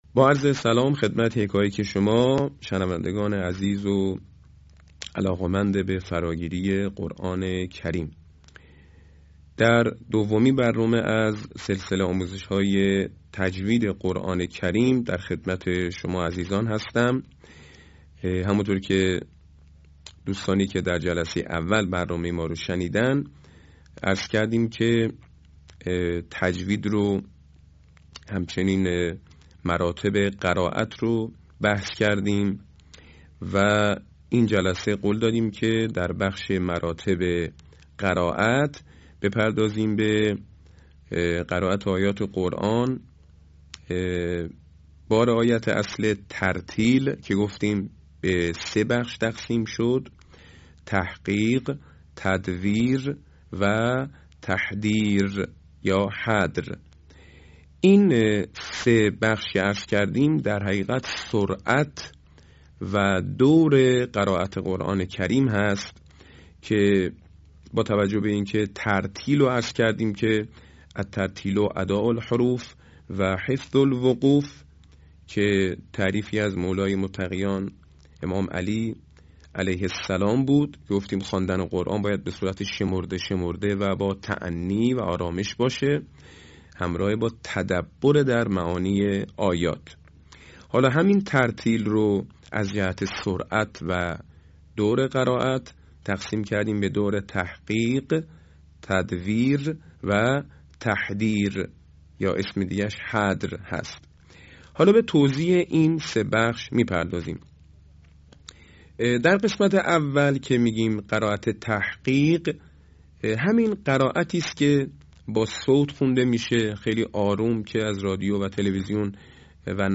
صوت | آموزش انواع تلاوت